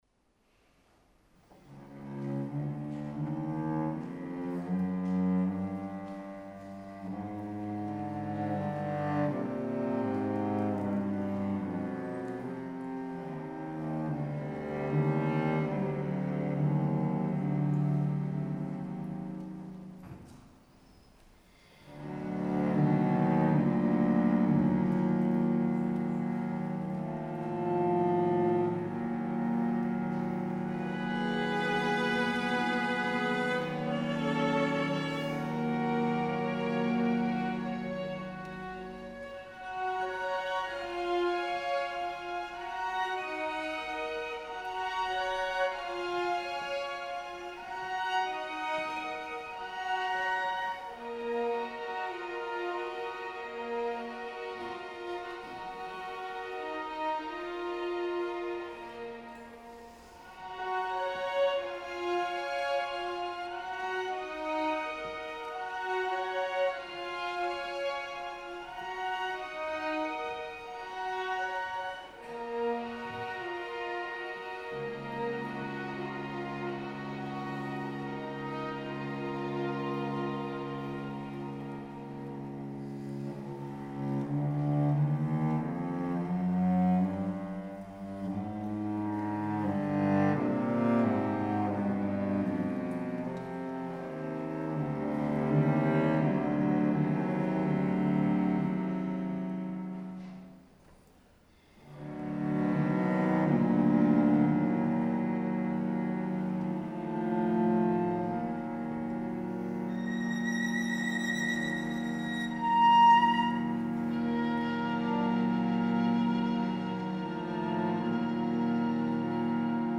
Concerto for violin and orchestra - West Cork Music
Venue: St. Brendan’s Church
Full/Chamber Orchestra
vn-solo, str
Irish Chamber Orchestra - [orchestra]